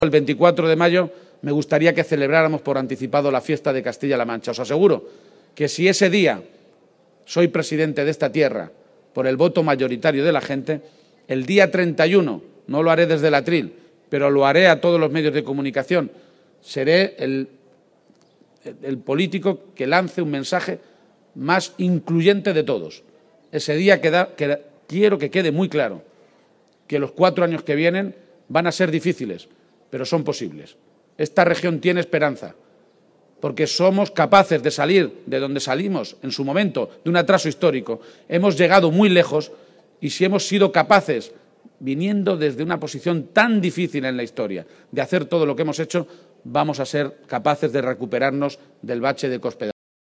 En el acto público del PSOE en Caudete, también anunció que si el próximo 31 de Mayo, Día de Castilla-La Mancha, es presidente de la Comunidad Autónoma,- gracias al voto mayoritario de la ciudadanía-, su discurso será “incluyente”, remarcando que la próxima Legislatura representará “la esperanza de una Región que si ha sido capaz de llegar tan lejos en la historia, partiendo de una posición tan atrasada, podremos sobreponernos al bache que ha supuesto Cospedal”.